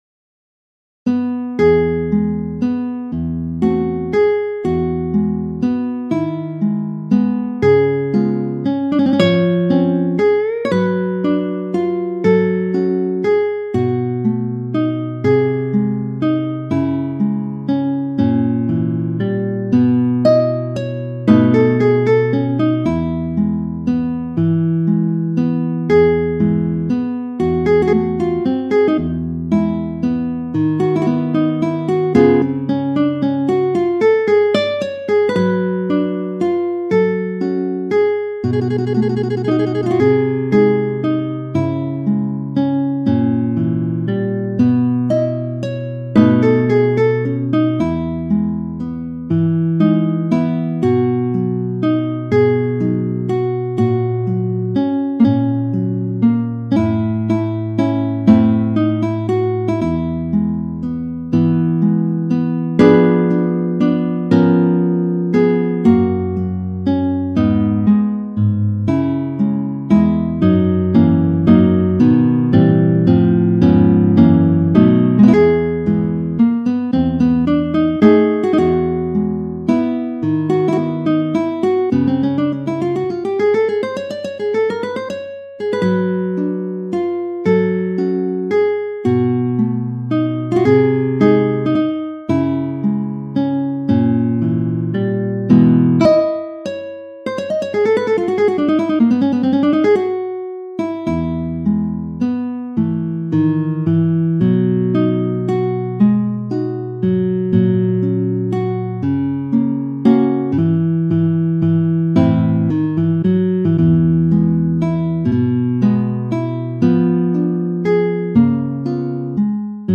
chopin-tarrega-nocturne-op9-no2-guitar.mp3